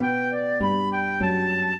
flute-harp
minuet1-3.wav